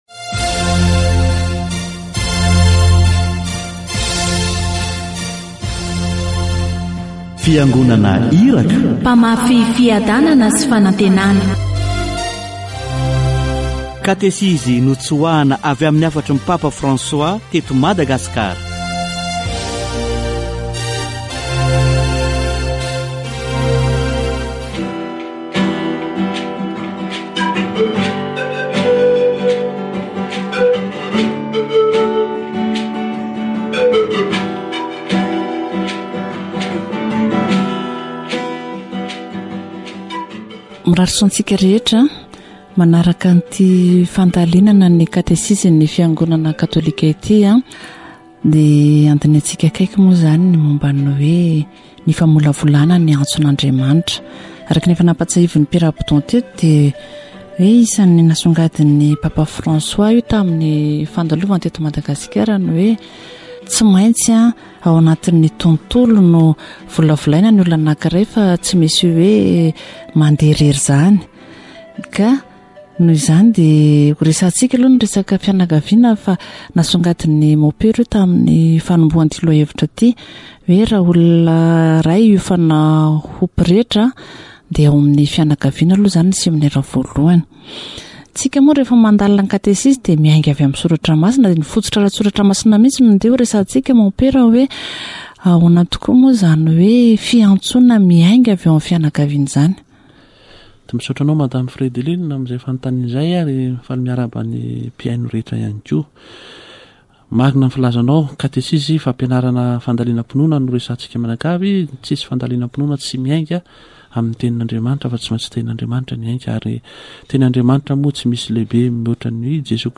Catechesis on "nurturing the priestly vocation"